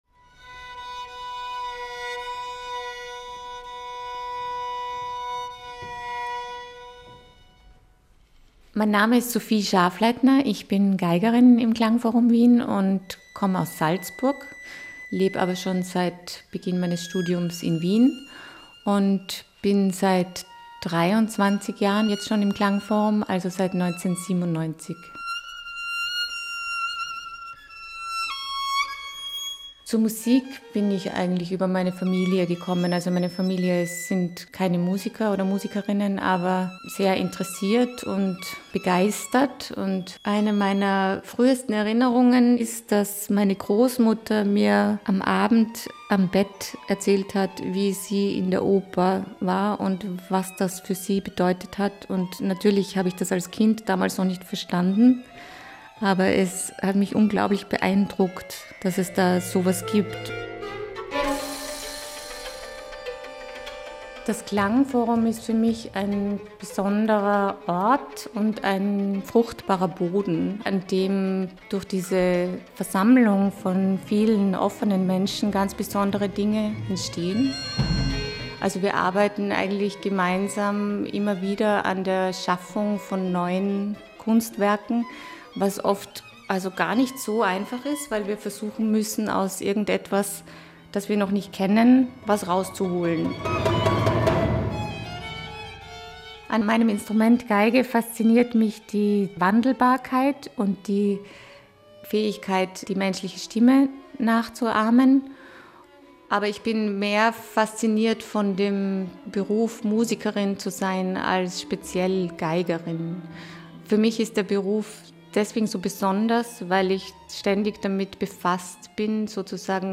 Ö1 Audio-Porträt https